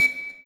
bbBeep.wav